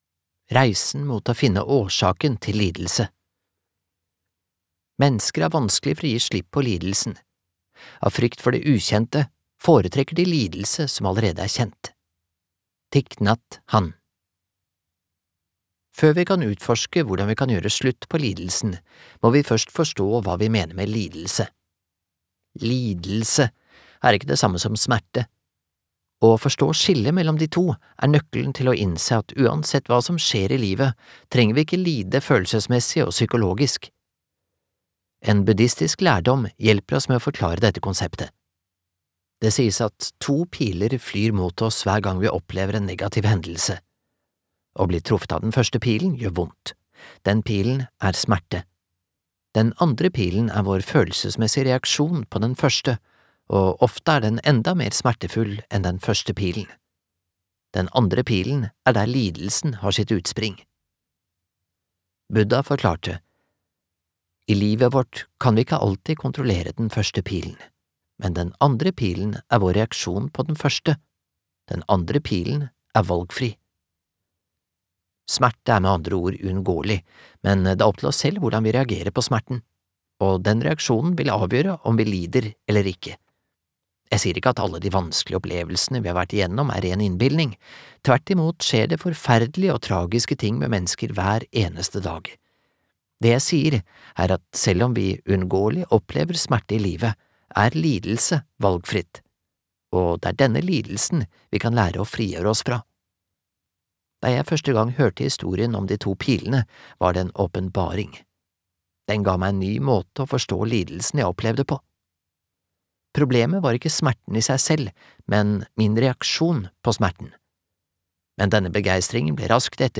Ikke tro på alt du tenker - hvorfor tankene dine er starten og slutten på mentale smerter (lydbok) av Joseph Nguyen